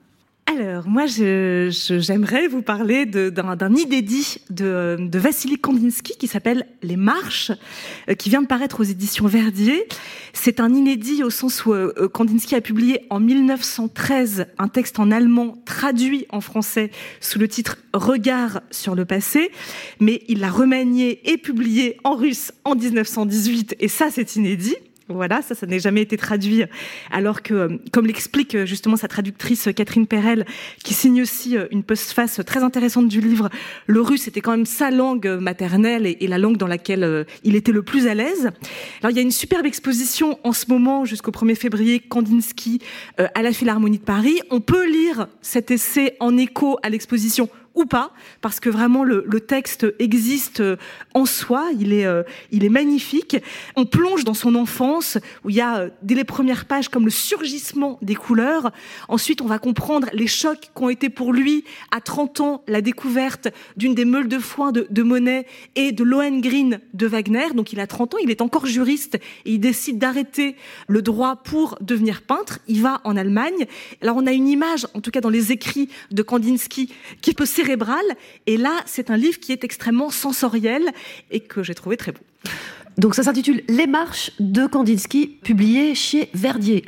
Radio et télévision
« Le masque et la plume », France Inter, 7 décembre 2025 : Les Marches, lecture conseillée par Anna Sigalevitch :